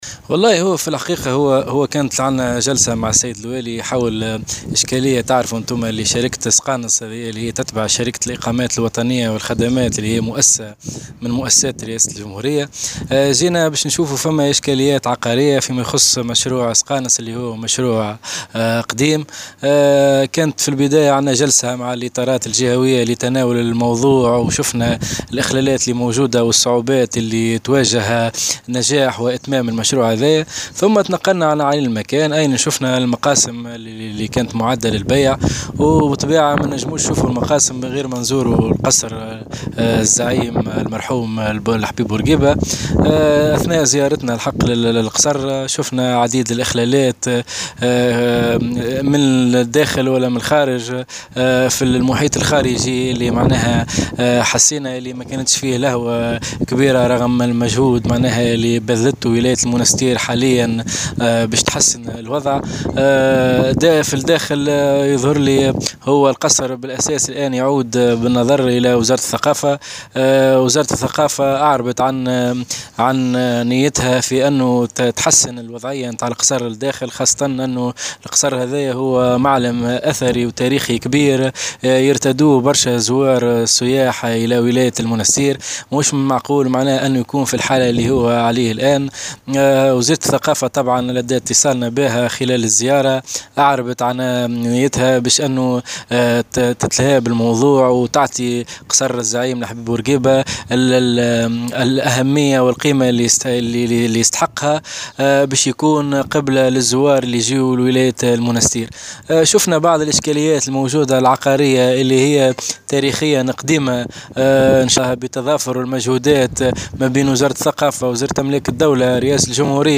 وأكد الحفيان في تصريح لمراسل الجوهرة أف أم، أنه لاحظ "عديد الإخلالات" سواء وسط القصر أو في محيطه الخارجي، على الرغم من الجهود المبذولة من قبل الولاية لصيانة هذا قصر سقانص بما يمثله من رمزية أثرية وتاريخية.